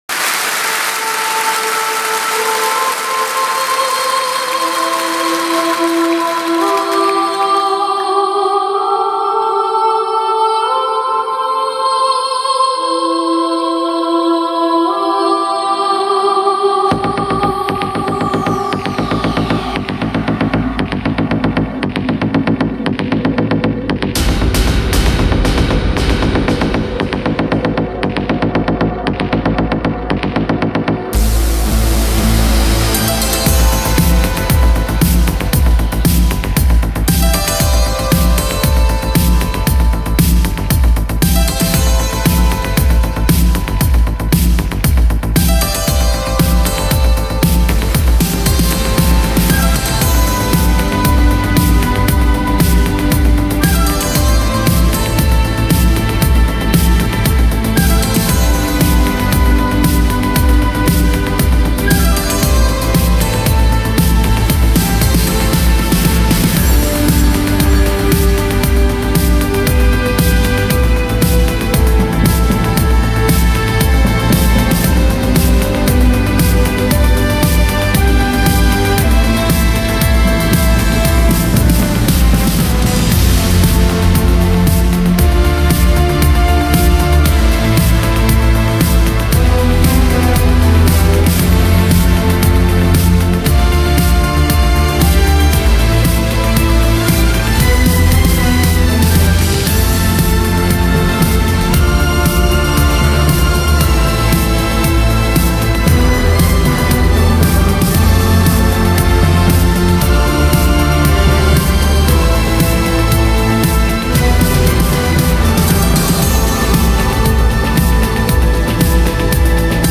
德国电音迷幻
Genre: Electronic, Ambient, Downtempo, Chillout, Classical